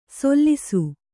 ♪ sollu